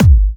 VEC3 Bassdrums Trance 29.wav